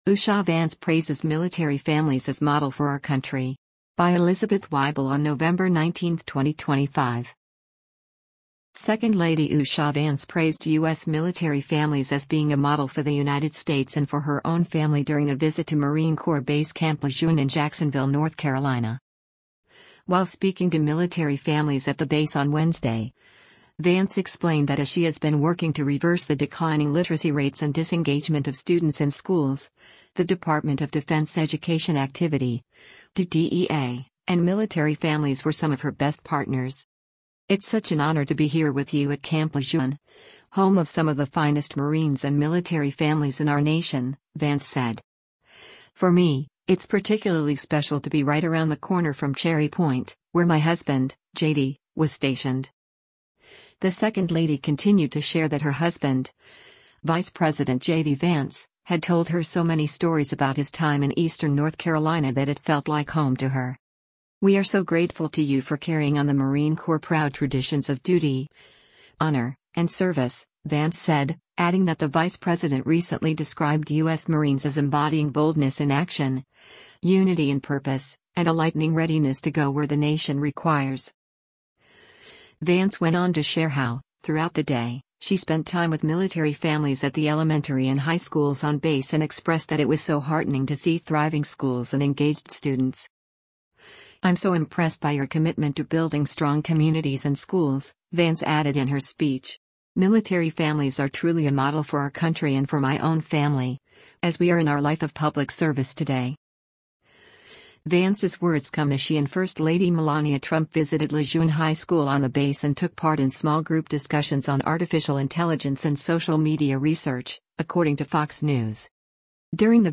US Second Lady Usha Vance speaks to members of the military at Marine Corps Air Station Ne